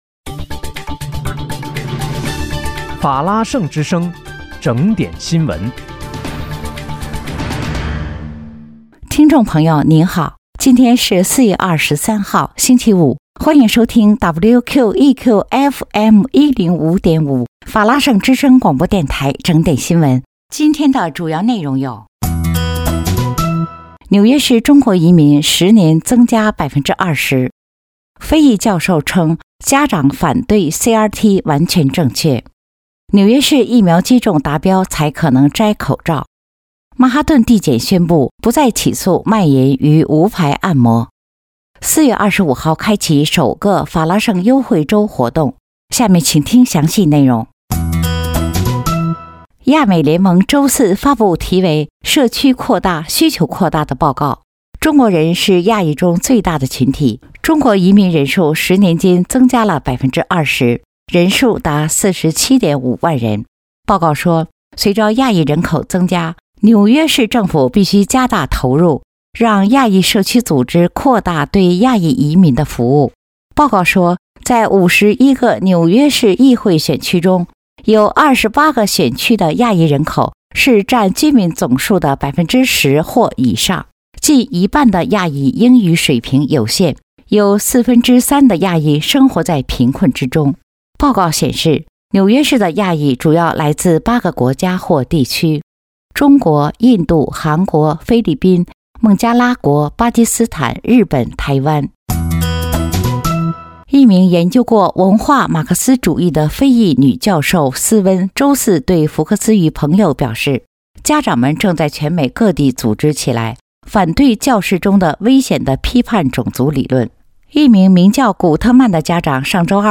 4月23日（星期五）纽约整点新闻
听众朋友您好！今天是4月23号，星期五，欢迎收听WQEQFM105.5法拉盛之声广播电台整点新闻。